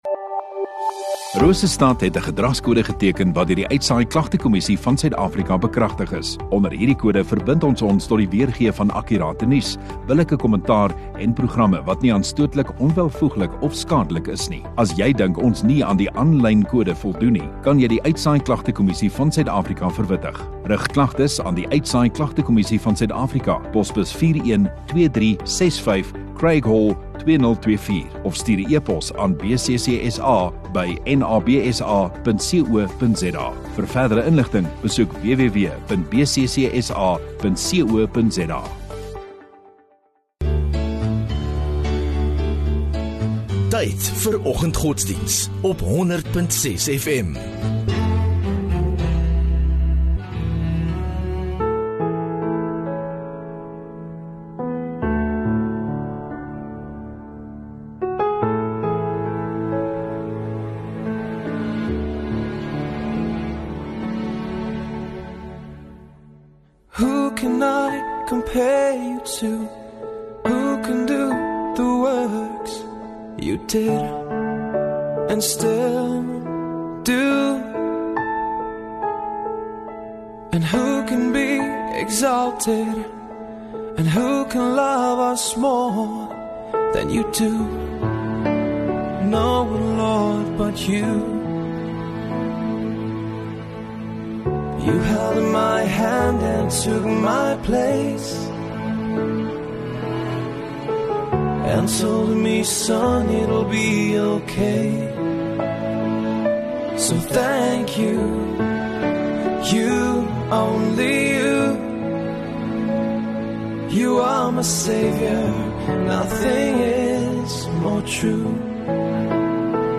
10 Feb Maandag Oggenddiens